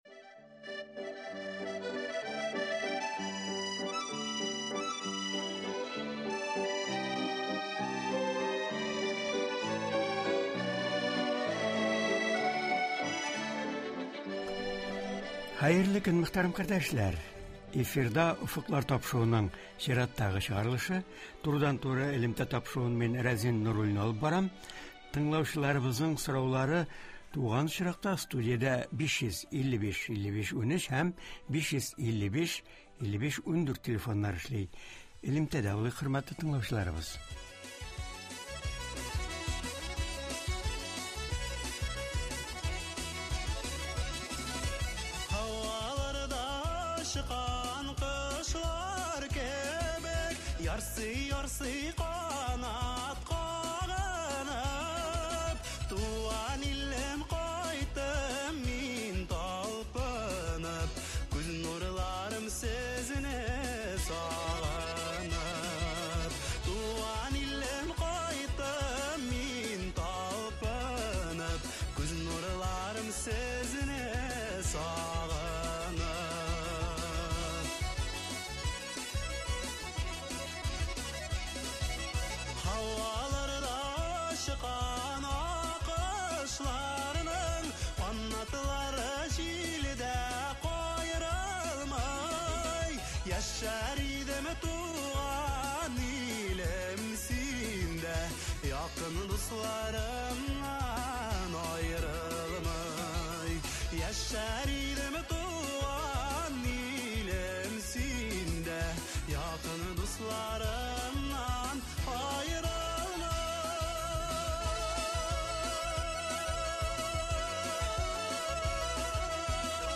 Аларны булдырмас өчен республикабыз хуҗалыкларында нинди эшләр башкарыла? Болар хакында турыдан-туры эфирда Татарстан республикасы Министрлар Кабинеты каршындагы ветеринария идарәсе җитәкчесе Алмаз Хисаметдинов сөйләячәк, үзенең киңәшләрен җиткерәчәк, тыңлаучыларны кызыксындырган сорауларга җавап бирәчәк.